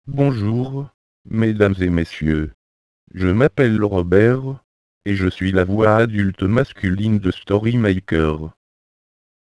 • High quality French speech engine greatly improves children’s pronunciation
• Six different French voices, male and female: click here to listen (briefly) to
• Voices can be made to speak more slowly for beginners to follow